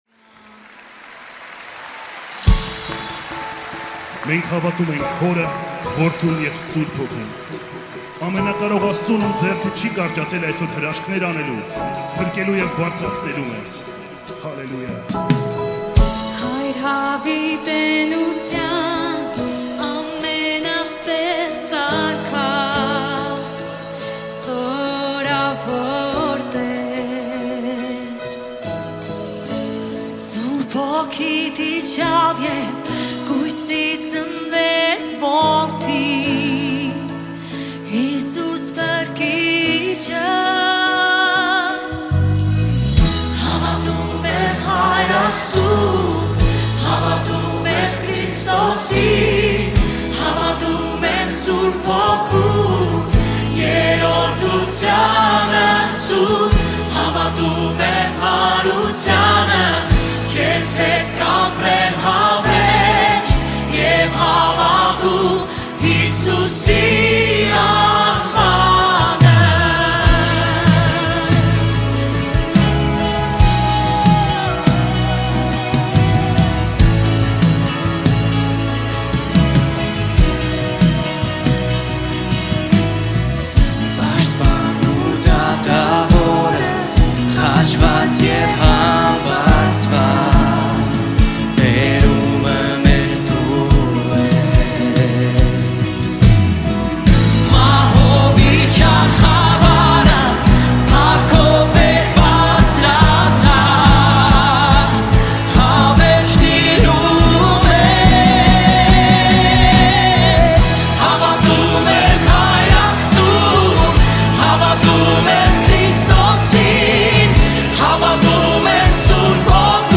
94 просмотра 140 прослушиваний 1 скачиваний BPM: 72